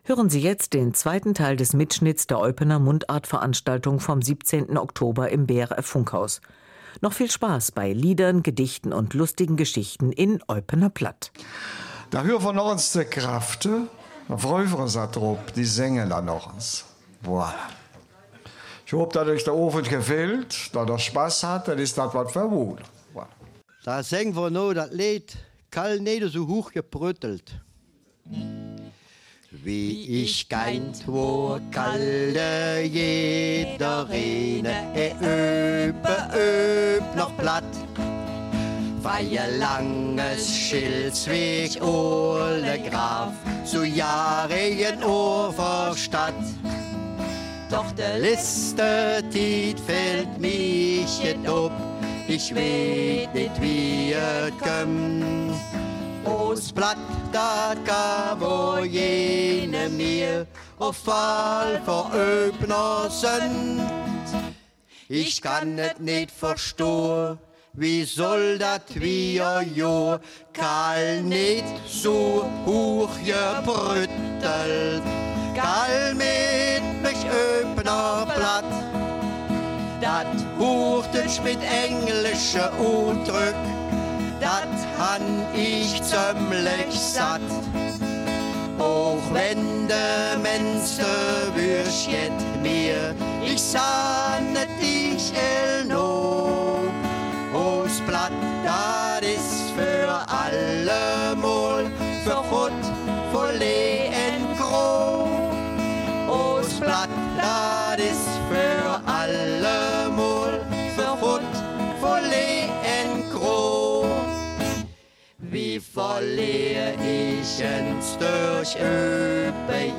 Die Eupener Mundartveranstaltungen sorgen seit zehn Jahren für ein volles BRF-Foyer.
Ein zweistündiger Mitschnitt ist am Sonntag, dem 19. November, zwischen 14 und 16 Uhr auf BRF2 zu hören.